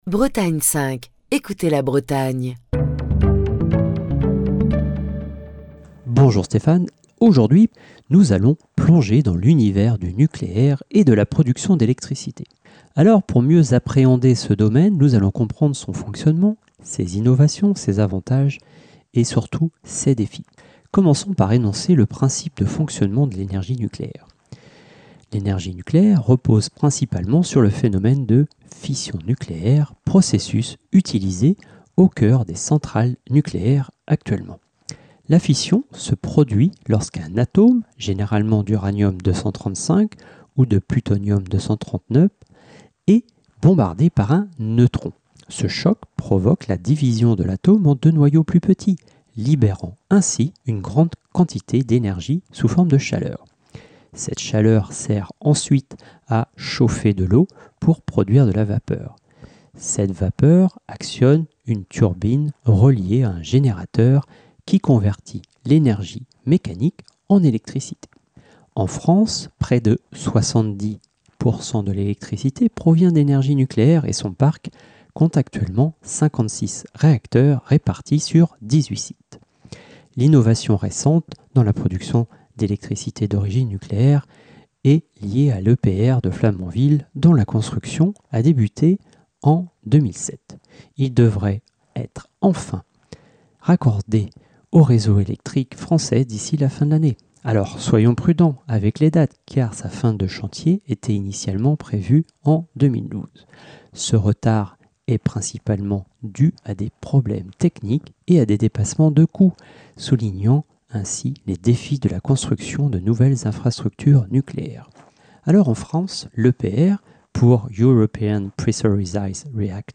Chronique du 18 septembre 2024.